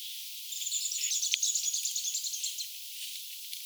hyvin erikoista oletettavasti ti-puukiipijän ääntelyä
oletettavasti_ti-puukiipijan_kiihtynytta_aantelya_kuului_ainakin_ti-puukiipijan_suunnasta.mp3